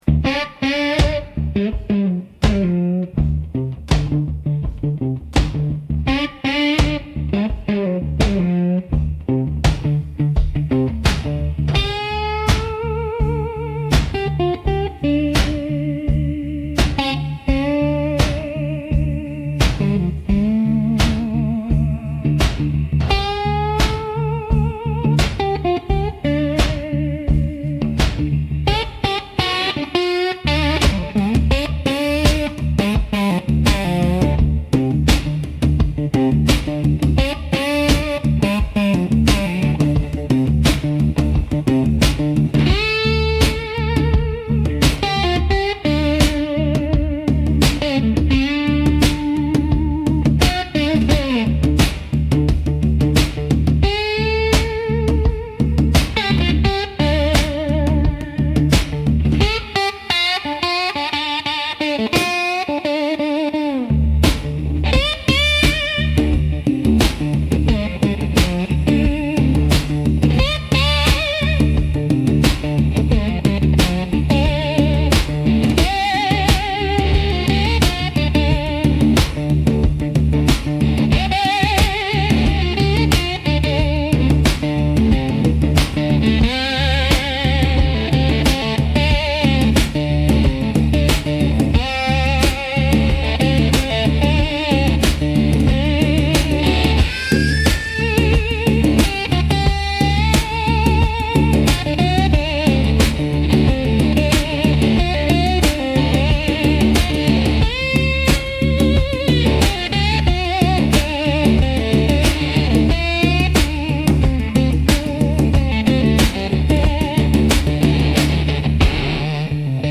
Instrumental - Chain-Gang Groove